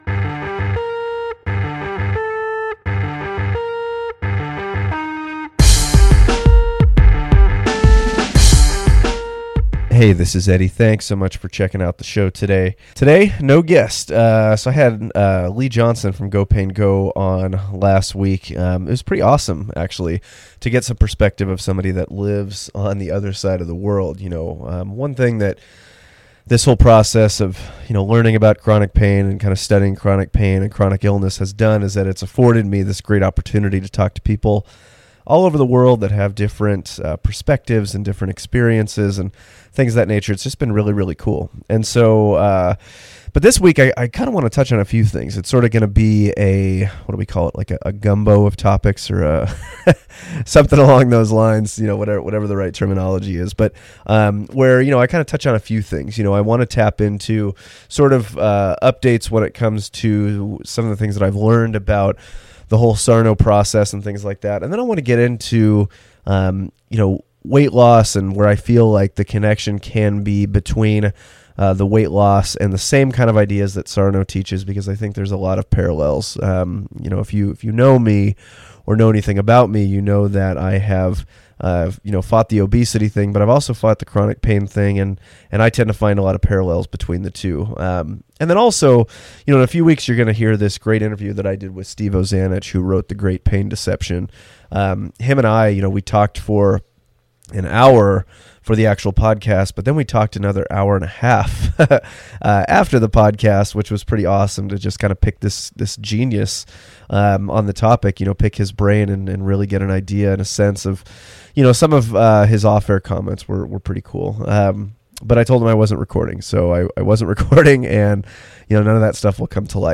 Excuse my destroyed voice in this episode - in the midst of a ManCold.